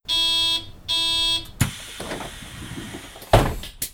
AbfahrtHaltestelleBus.wav